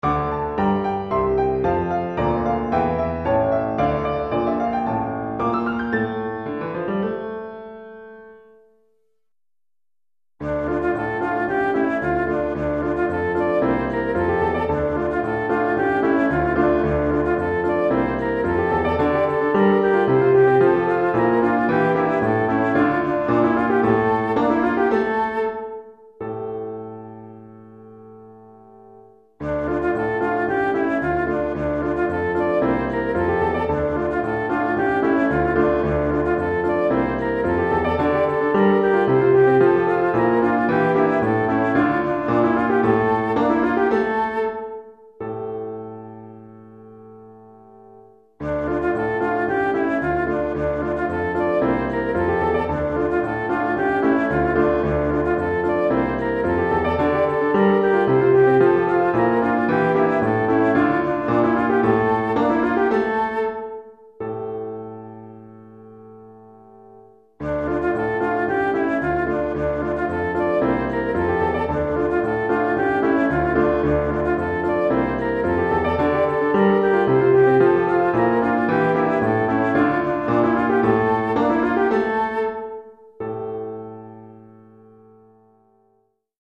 Children's Choeur 1 Piano